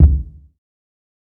TC3Kick8.wav